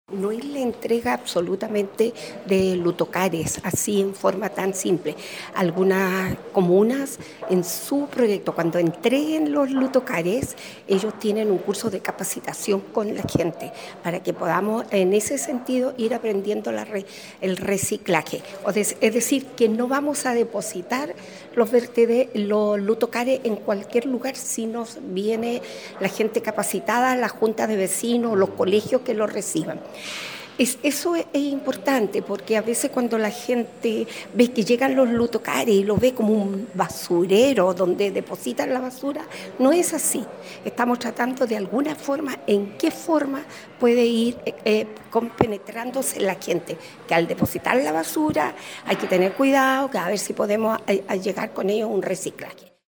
Carmen-Romo-Presidenta-Comisión-Medio-Ambiente.mp3